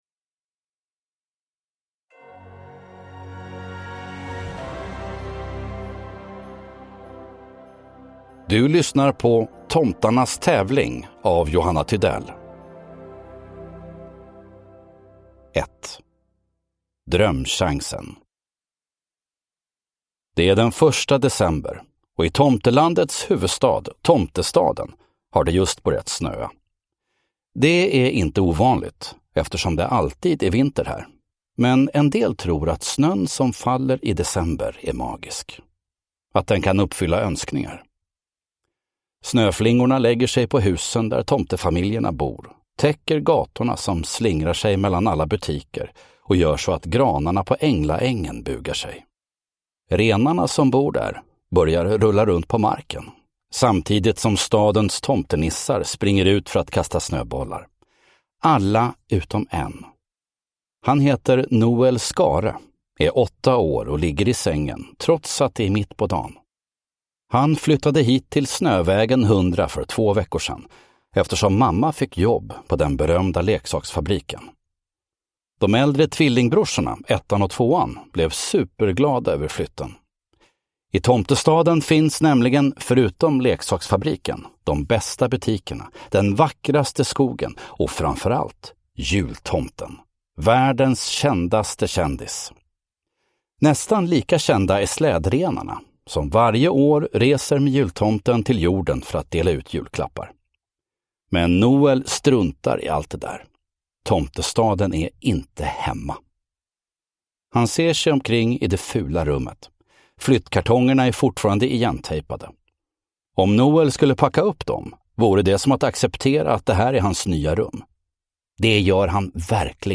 Tomtarnas tävling – Ljudbok
Uppläsare: Fredde Granberg